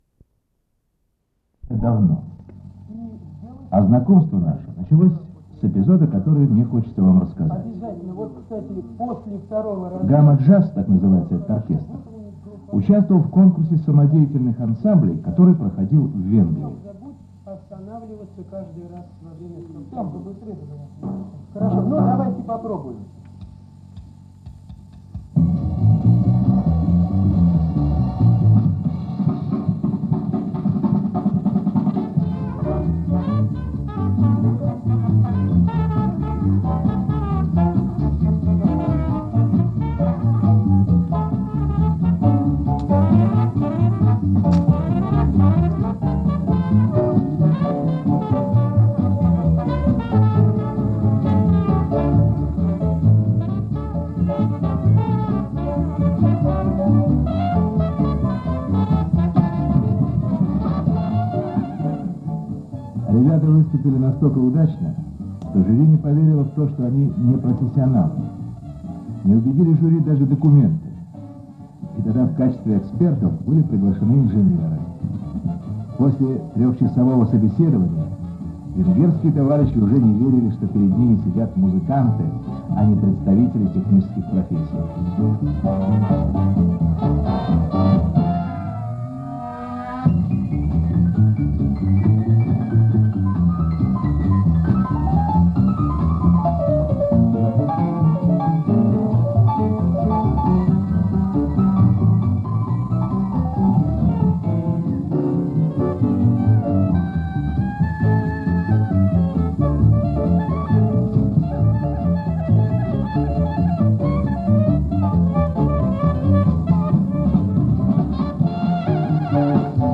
Диксиленд
труба
кларнет
тромбон
рояль
ударные
контрабас